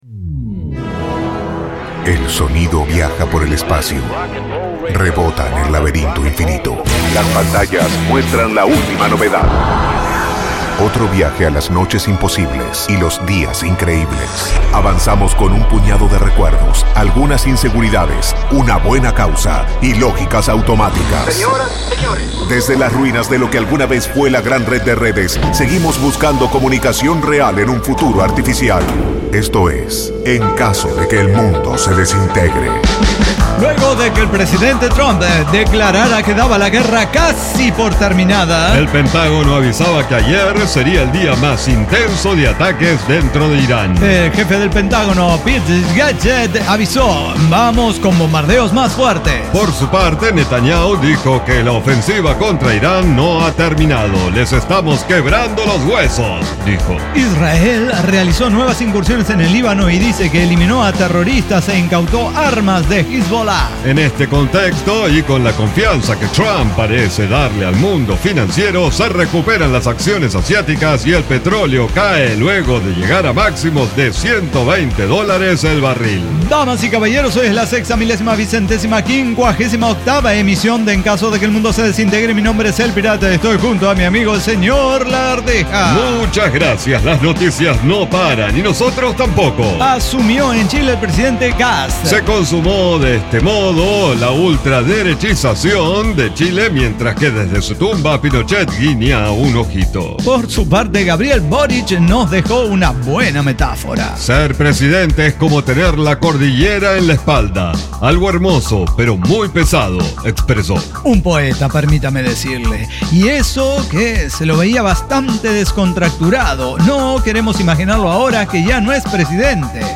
NO AI: ECDQEMSD Podcast no utiliza ninguna inteligencia artificial de manera directa para su realización. Diseño, guionado, música, edición y voces son de nuestra completa intervención humana.